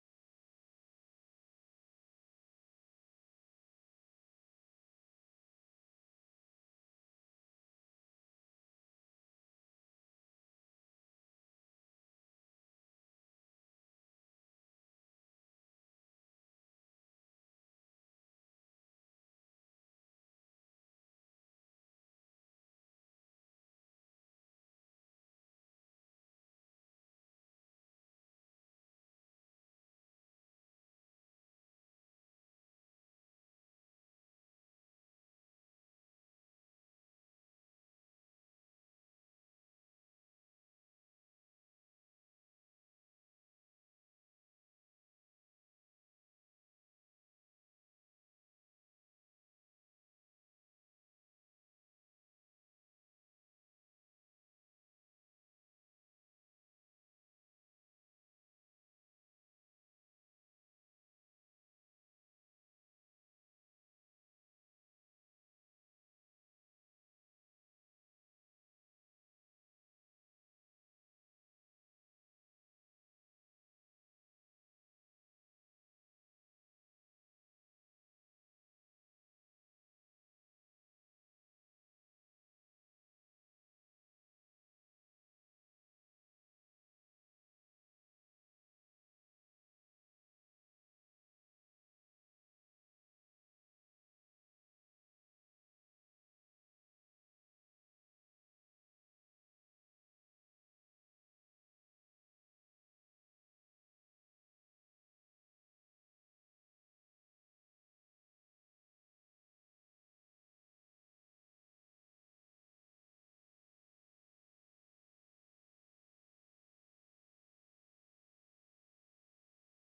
AMB_Scene04_Hammering.ogg